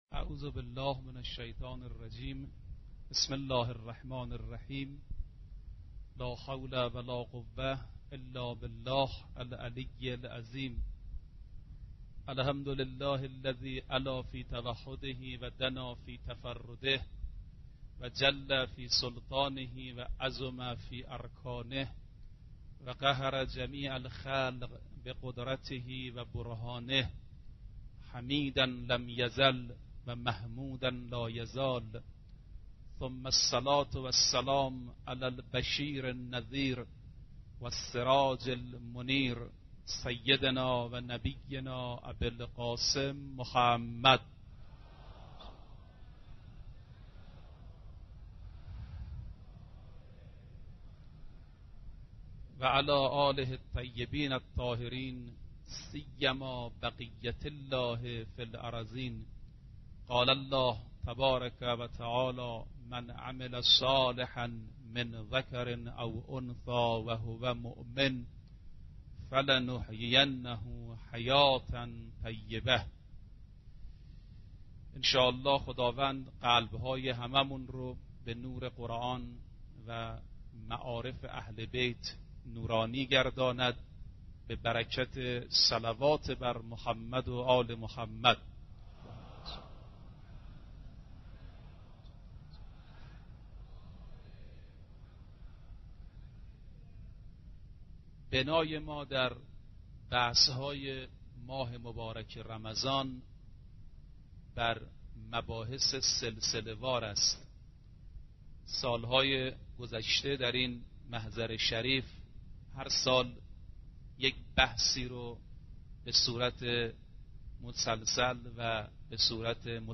آرشیو ماه مبارک رمضان - سخنرانی - بخش سوم